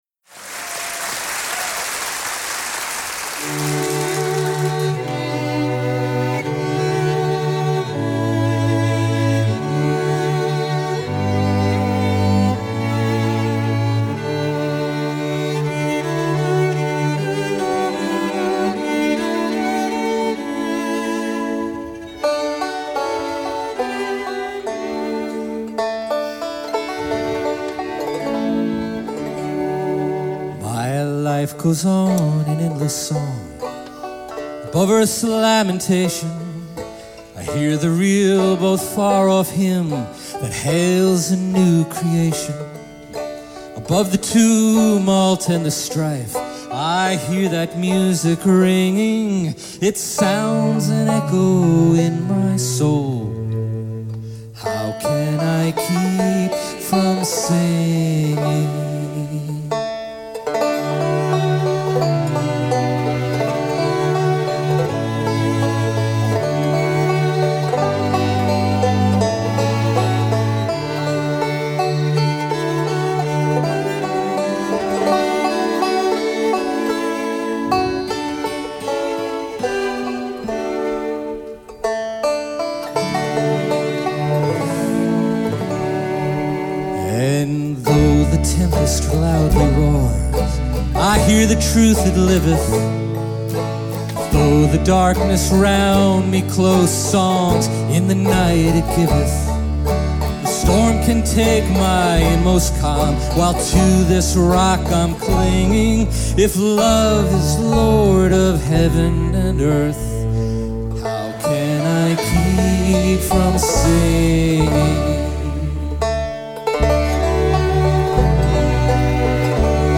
performing the airai with the Niles Quartet in concert in the way it would be presented in the Opera
06-How-Can-I-Keep-from-Singing-Live-Bonus.mp3